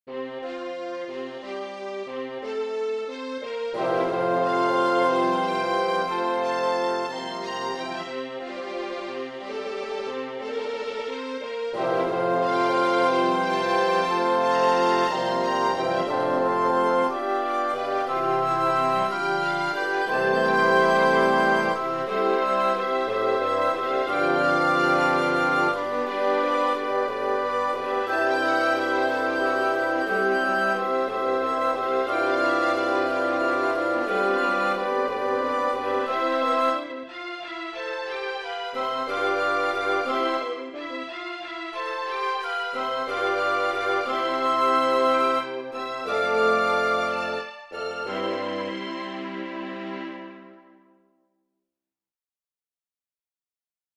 Arranging Symphonic, Vocal, and Piano Works for performance on Carillon
It is wonderful to keep moving all up and down the range of the carillon.
However, since bells volume need to be balanced and big bells are louder and more powerful, some octave switching is necessary.
Put theme in alto section and keep dominant ostenuto in bass bells. m 31 - 41, theme cascades down high to low.
Rhythms and supporting harmonies are in middle and bass layers, but kept very simple, so as not to overpwer the theme.